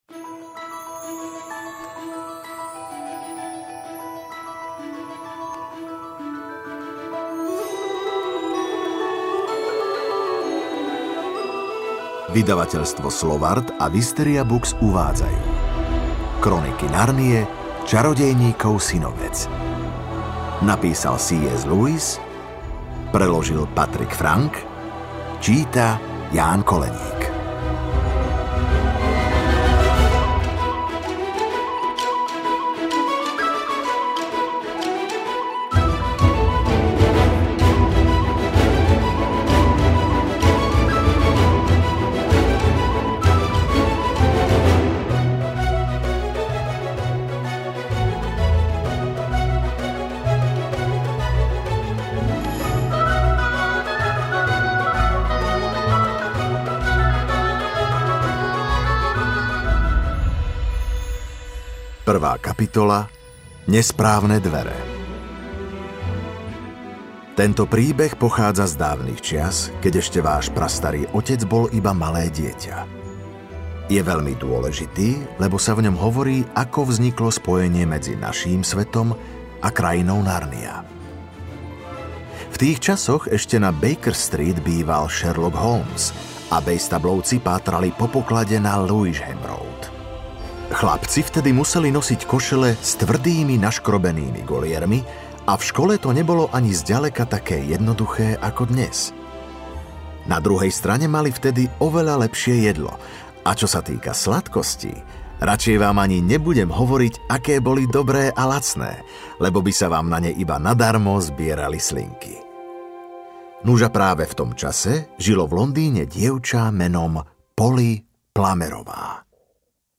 Čarodejníkov synovec audiokniha
Ukázka z knihy
• InterpretJán Koleník